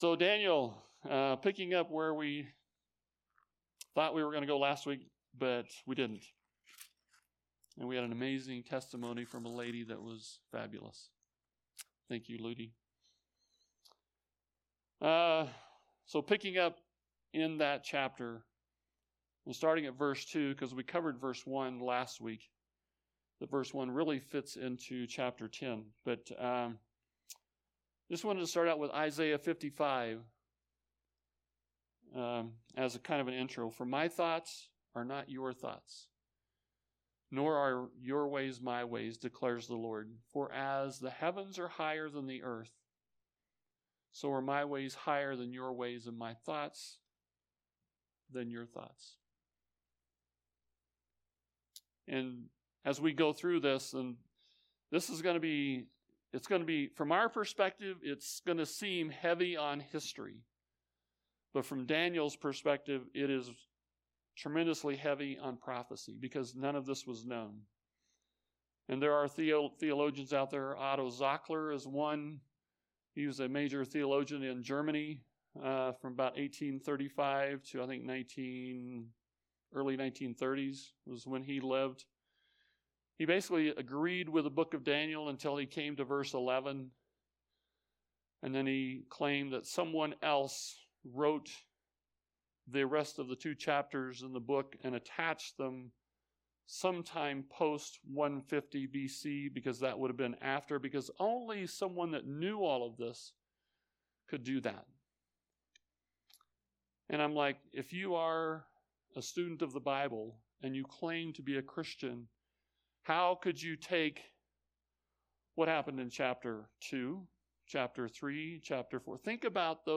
A sermon
Location: High Plains Harvest Church